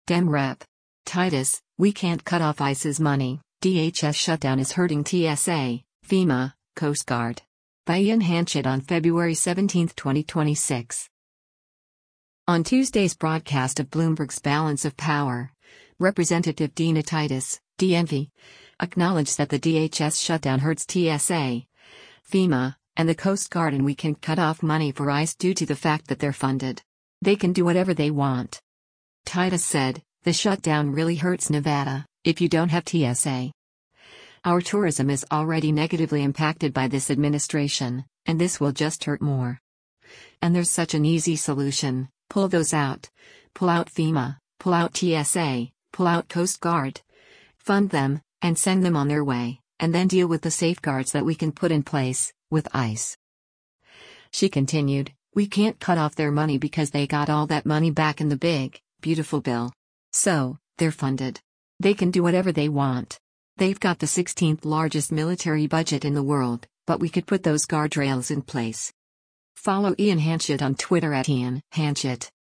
On Tuesday’s broadcast of Bloomberg’s “Balance of Power,” Rep. Dina Titus (D-NV) acknowledged that the DHS shutdown hurts TSA, FEMA, and the Coast Guard and “We can’t cut off” money for ICE due to the fact that “they’re funded. They can do whatever they want.”